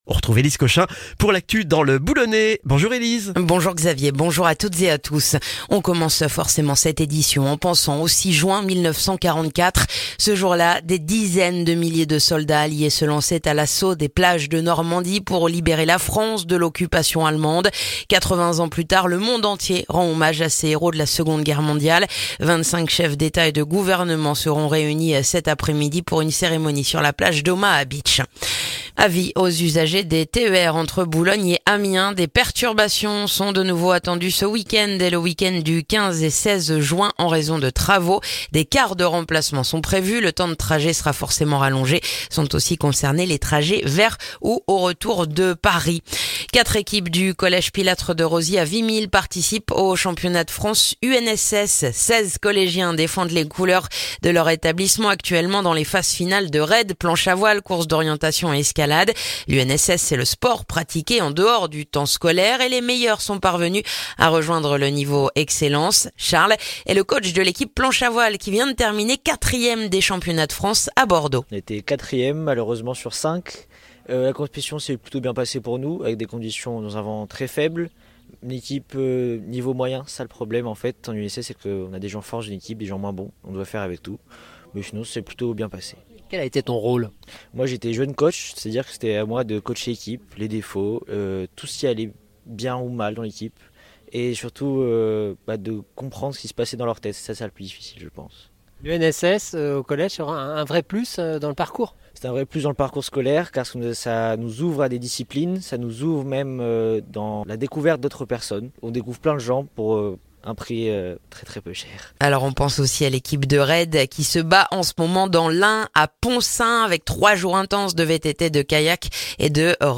Le journal du jeudi 6 juin dans le boulonnais